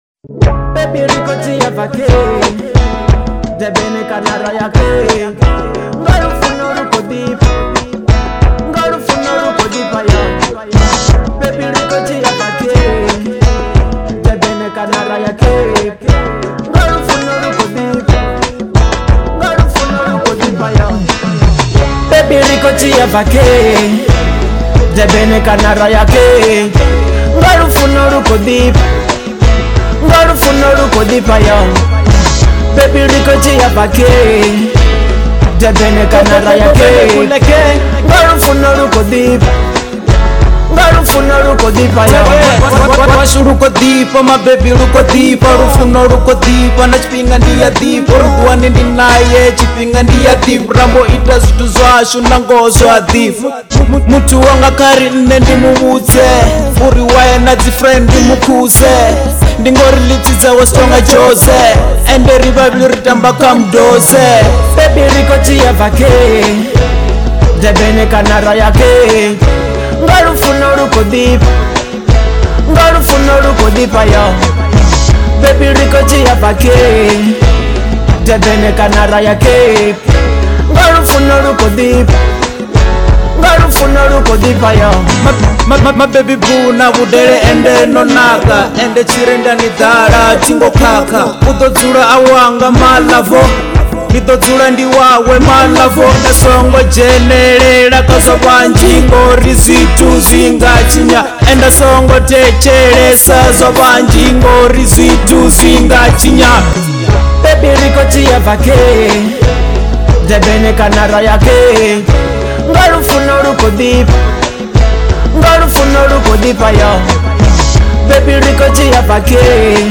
02:22 Genre : Venrap Size